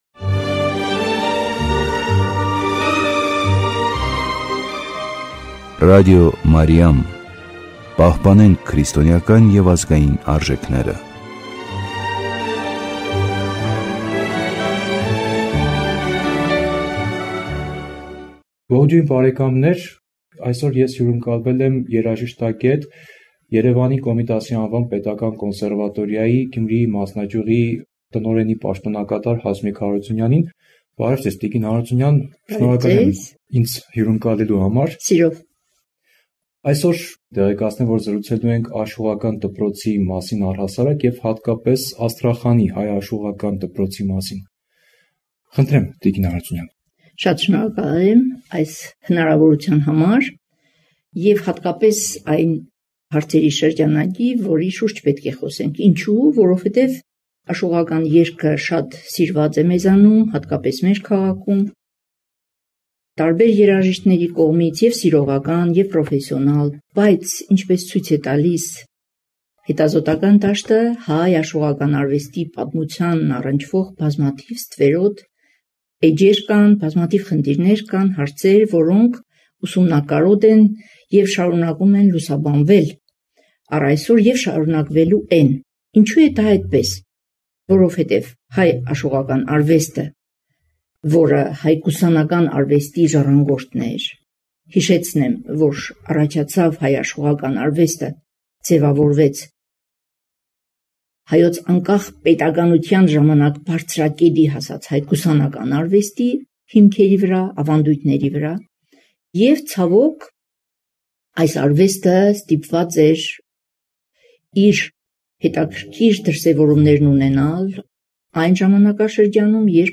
Աստրախանի հայկական համայնքը և նրանում գործած աշուղական կենտրոնը։ Փաստերը և խոր ուսումնասիրության անհրաժեշտությունը։ Զրուցում ենք երաժշտագետ